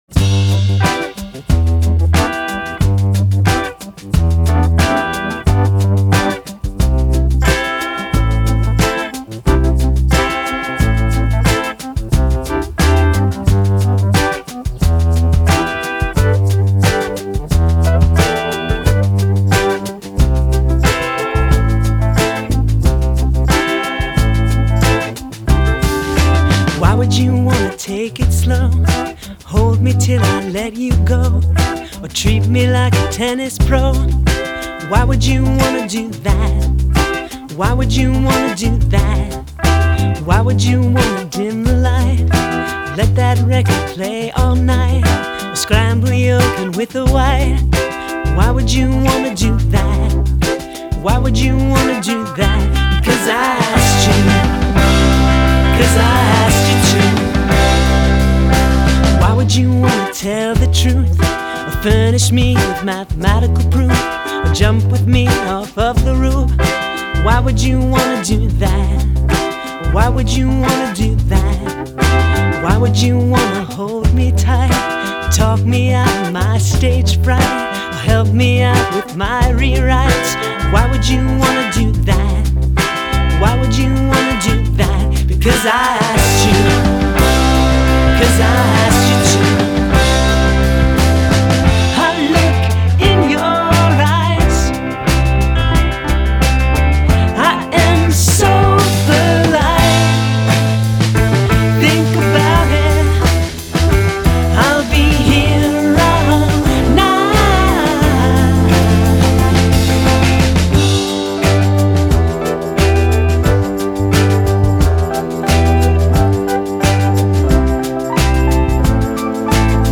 Genre: Indie, Alternative